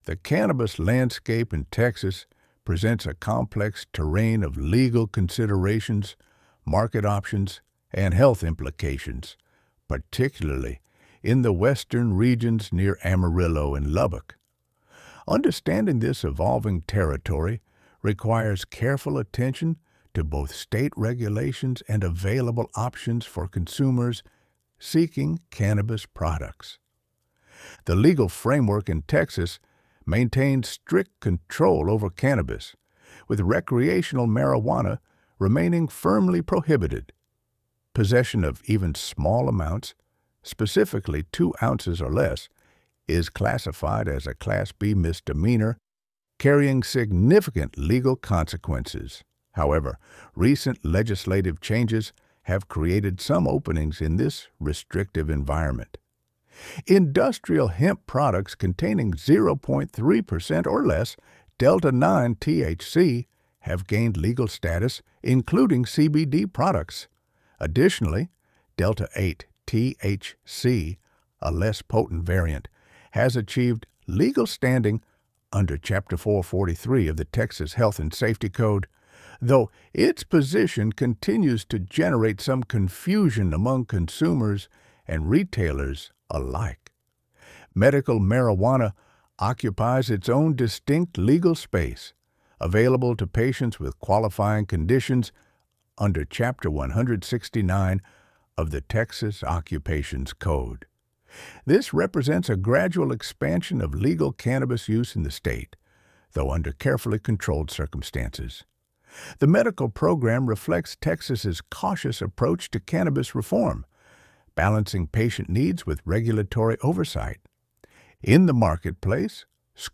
Join us as we explore top dispensaries, standout strains, and expert cultivation tips unique to the region. Featuring interviews with local growers, dispensary owners , and cannabis connoisseurs, we'll provide insights into the quality and variety of cannabis available in Western Texas.